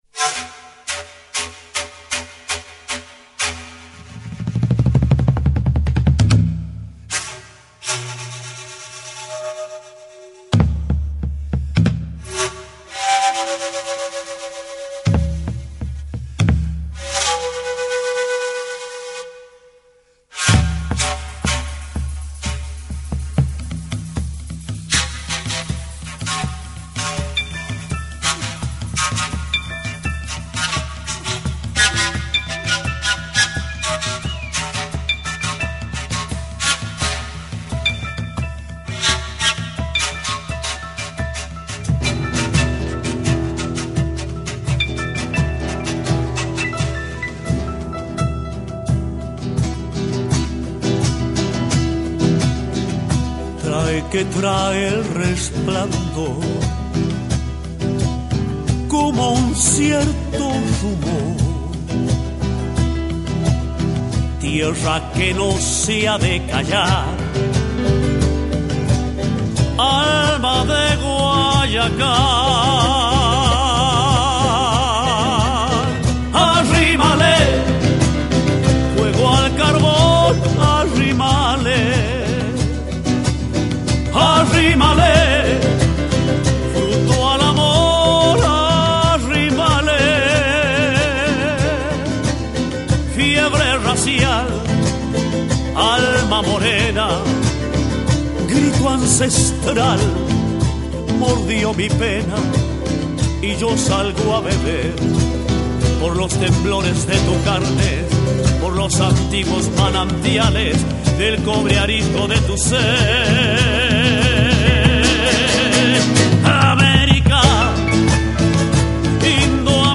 в исполнении аргентинского певца